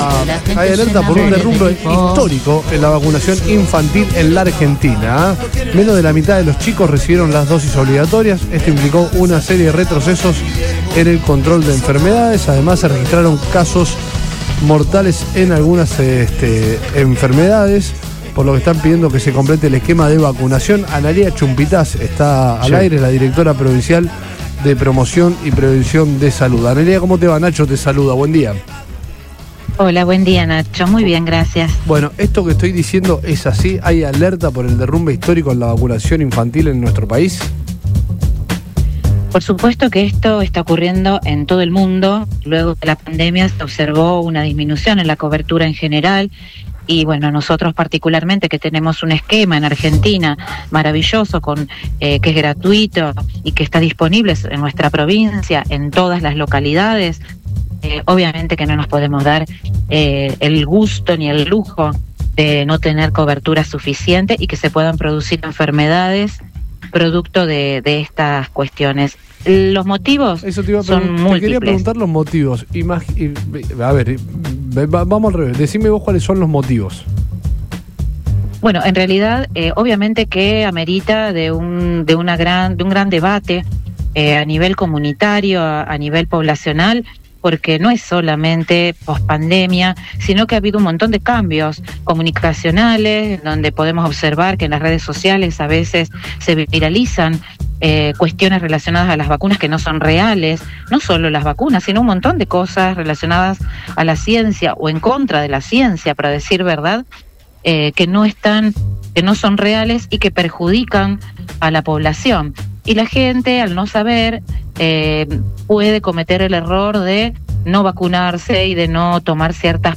Analia Chumpitaz, Directora Provincial de Promoción y Prevención de Salud, confirmó en Todo Pasa de Radio Boing 97.3 la alerta por un “derrumbe histórico” en la vacunación infantil en Argentina.